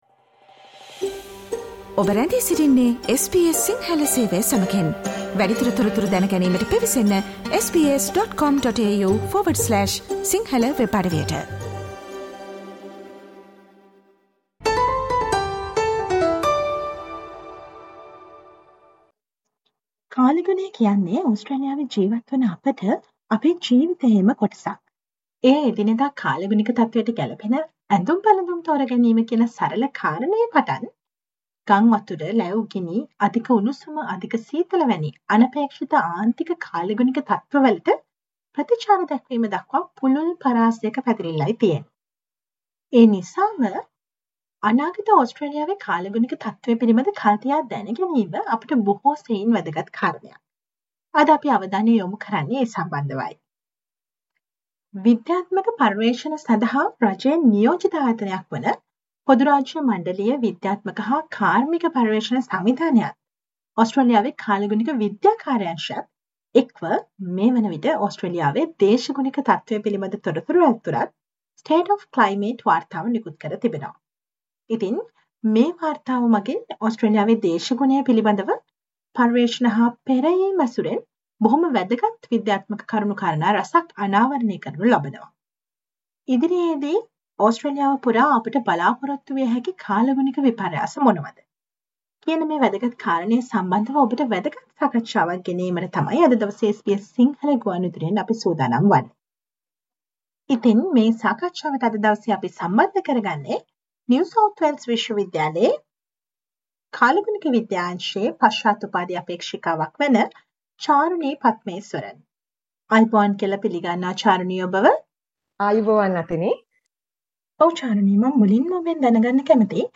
CSIRO and the Bureau of Meteorology has released the State of climate 2022 report. Listen to this SBS Sinhala interview to know on what are the expected climate changes over Australia in recent future?